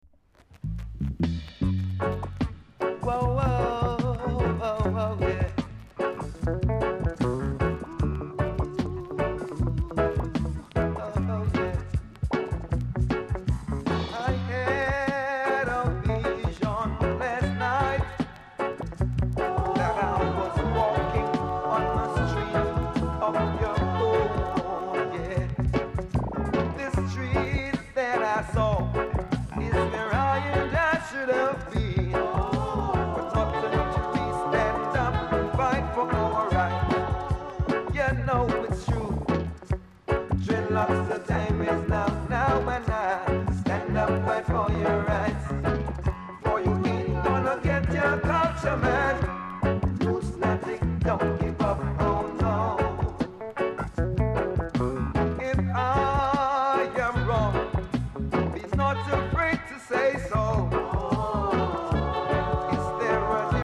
※イントロで少しジリジリあります。ほか多少小さなノイズはありますが概ね良好です。盤も概ねキレイです。
コメント KILLER ROOTS!!